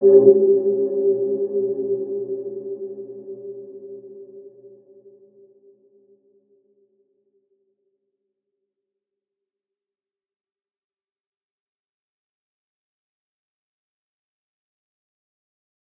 Dark-Soft-Impact-G4-p.wav